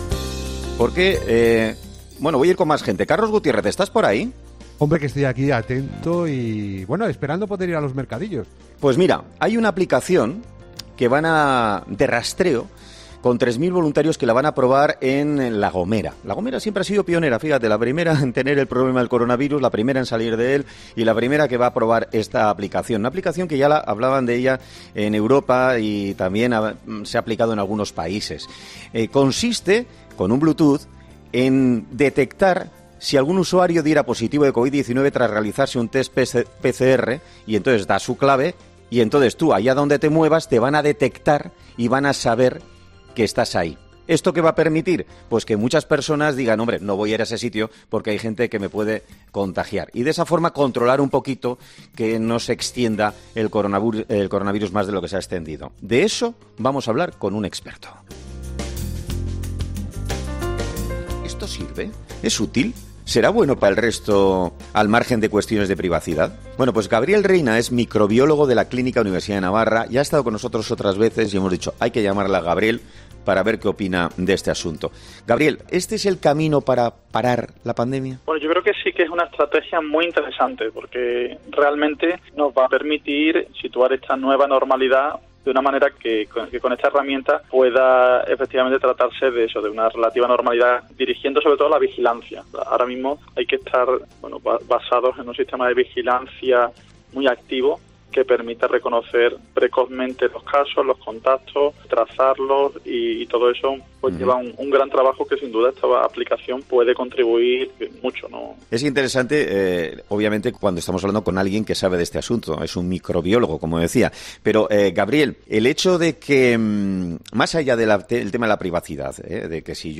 No obstante, recuerda el microbiólogo que no podemos olvidarnos de “las normas más elementales de prevención como taparnos la boca al toser o estornudar, lavarnos las manos y por supuesto el uso de mascarillas”.